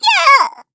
Taily-Vox_Damage_kr_05.wav